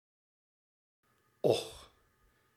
The open-mid back rounded vowel, or low-mid back rounded vowel,[1] is a type of vowel sound, used in some spoken languages.
Dutch Standard Belgian[18] och
[ʔɔˤx] 'alas' 'Very tense, with strong lip-rounding',[19] strongly pharyngealized[20] (although less so in standard Belgian[21]) and somewhat fronted.[18][22] See Dutch phonology